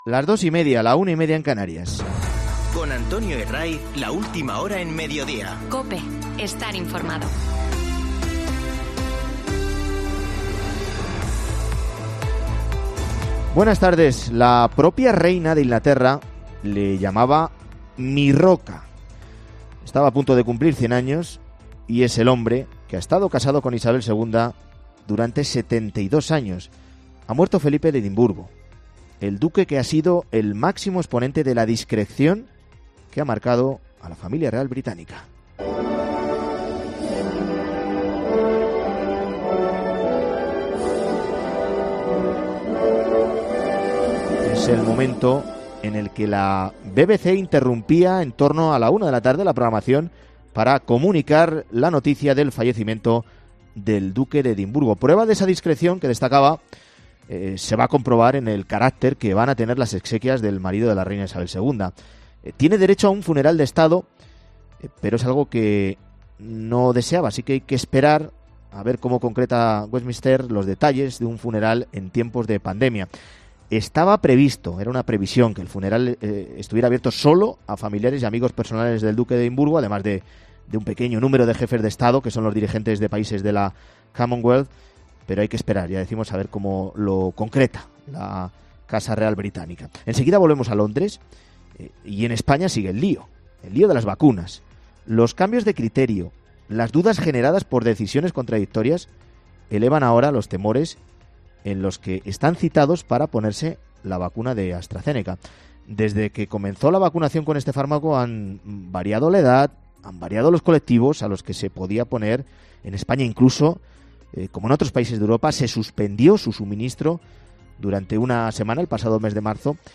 Escuchamos el momento en el que la BBC interrumpía la programación para comunicar la noticia del fallecimiento del Duque de Edimburgo.